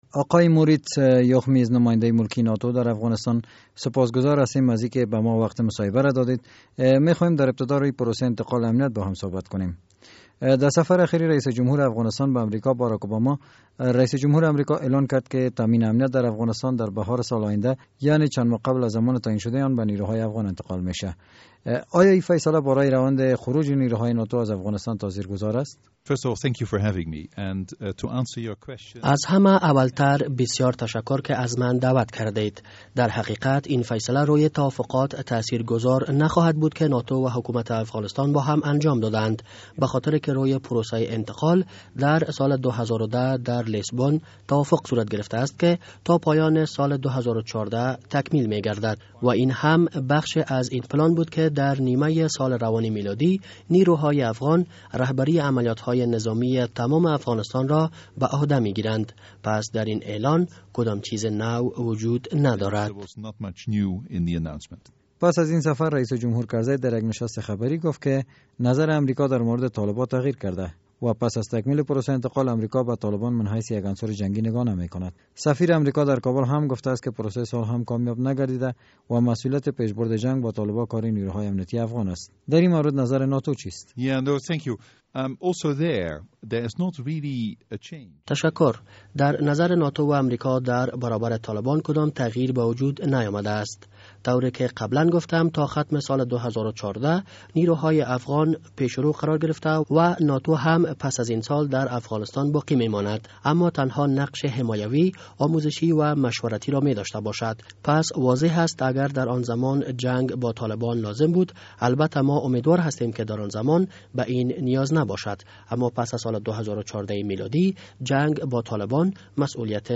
مصاحبهء اختصاصی با نمایندهء ملکی ناتو در افغانستان